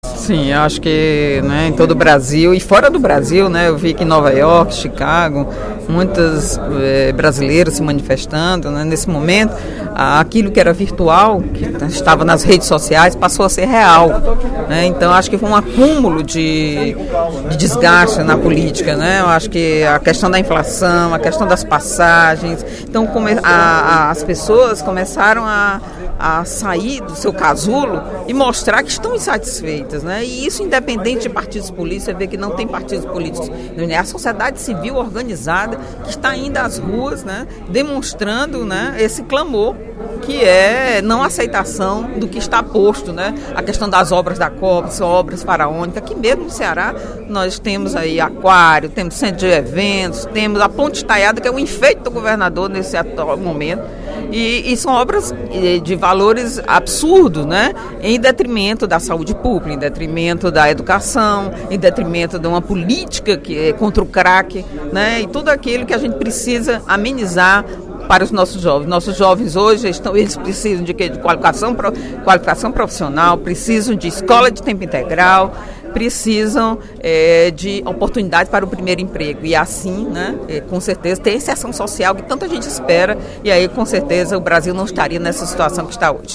Durante o primeiro expediente da sessão plenária desta terça-feira (18/06), a deputada Eliane Novais (PSB) destacou as manifestações realizadas ontem em todo o País.